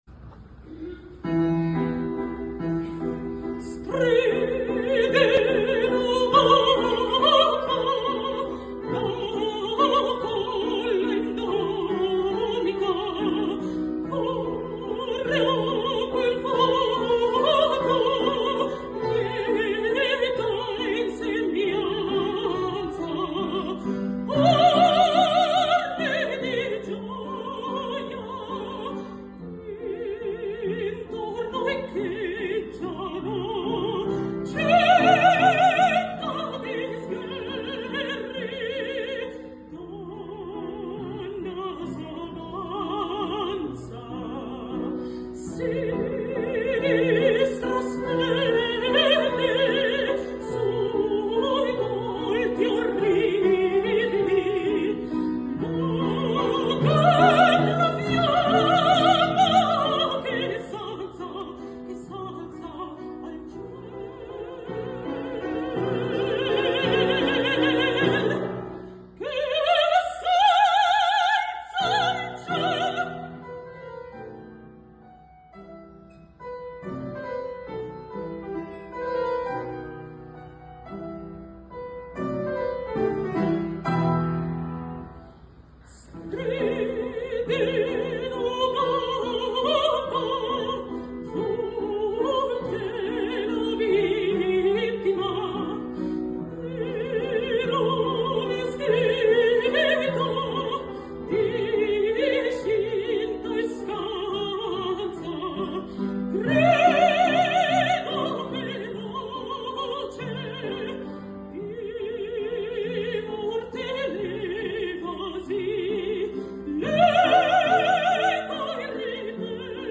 Incontro con la giovane mezzosoprano